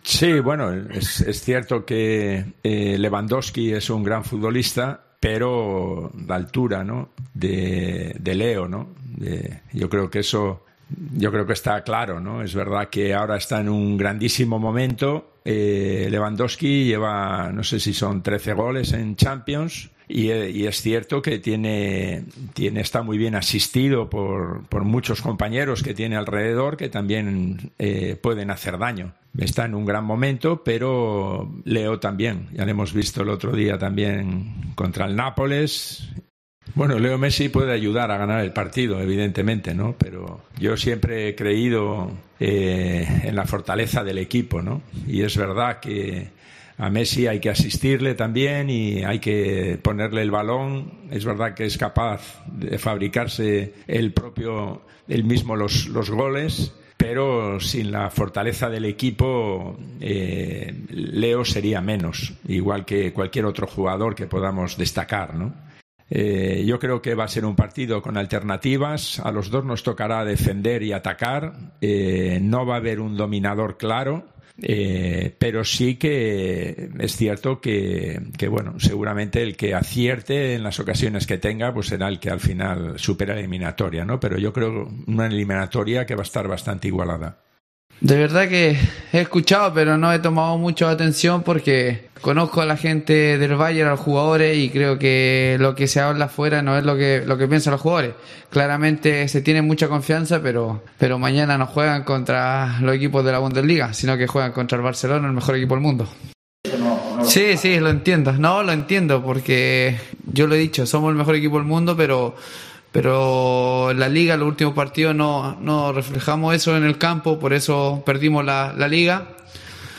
AUDIO: Les declaracions de l'entrenador blaugrana i d'Arturo Vidal en el prèvia dels quarts de final de Champions contra el Bayern de Munich al...